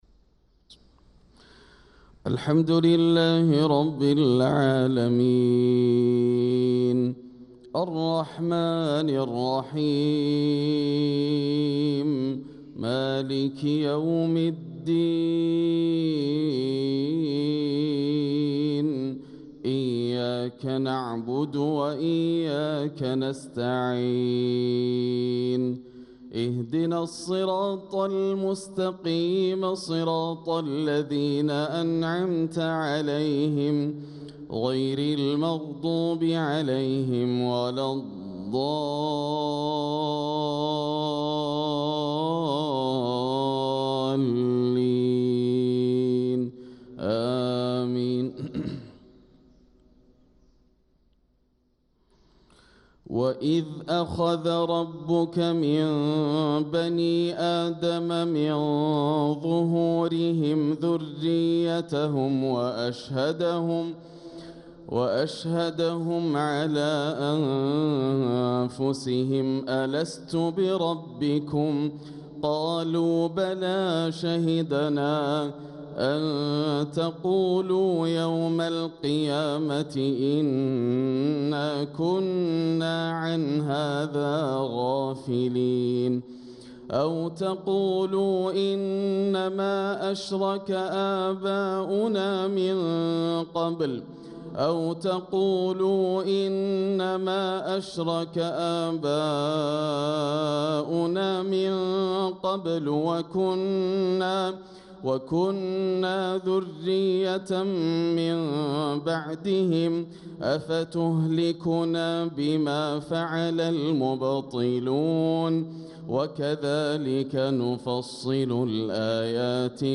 صلاة الفجر للقارئ ياسر الدوسري 26 ربيع الأول 1446 هـ
تِلَاوَات الْحَرَمَيْن .